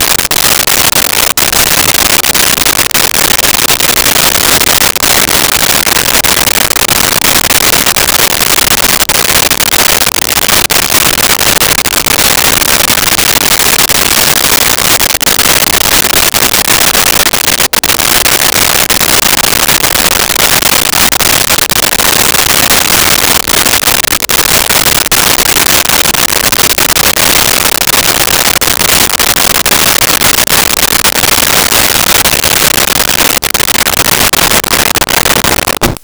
Wind Light.wav